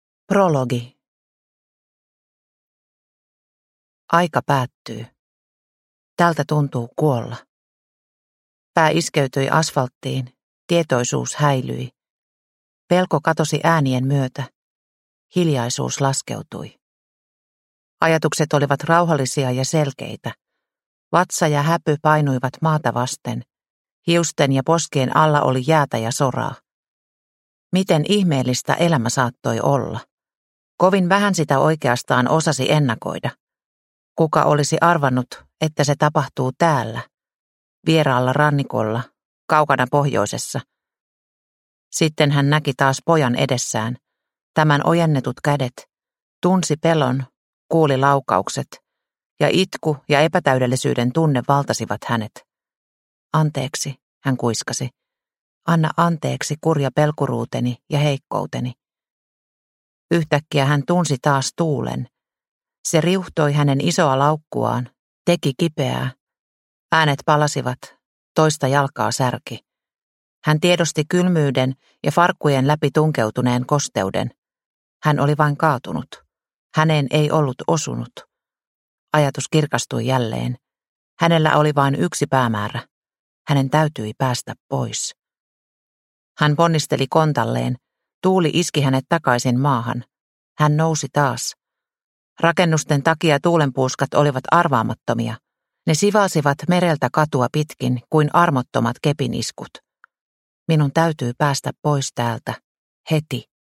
Paratiisi – Ljudbok – Laddas ner